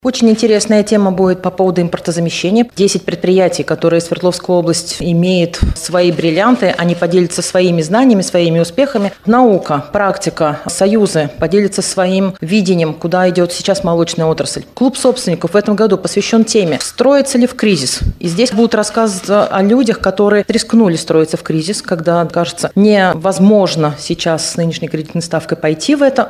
на пресс-конференции ТАСС-Урал.